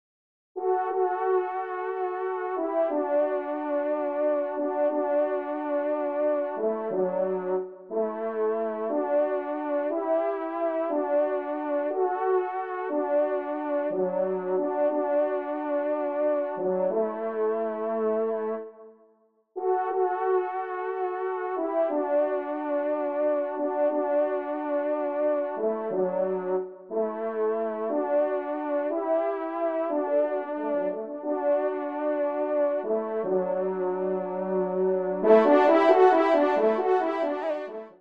Genre : Musique Religieuse pour  Quatre Trompes ou Cors
Pupitre 2°Trompe